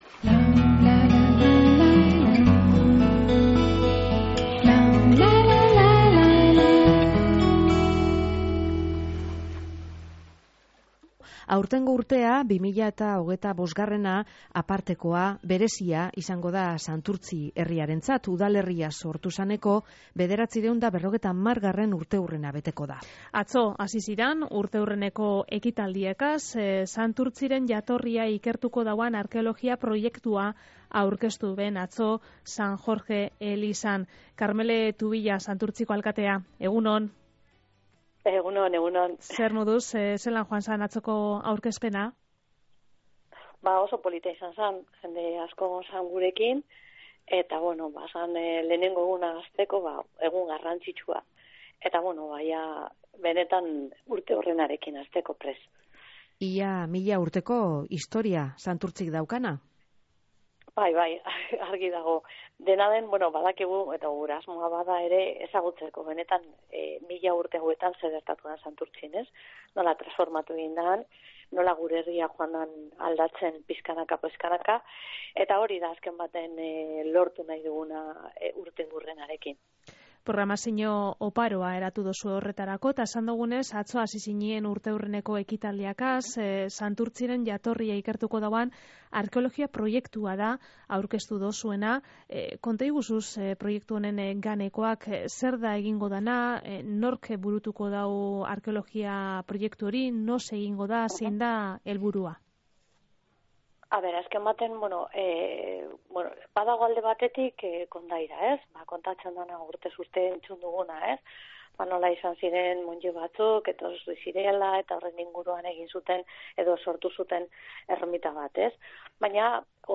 Santurtzi udalerria sortu zaneko 950. urteurrena ospatuko da urtean zehar. Karmele Tubilla alkateak Lau Haizetara irratsaioan esan dauanez, Santurtziko identidadearen jatorria zein dan erakustea da urte honetan zehar egingo diran ekitaldien helburu nagusia.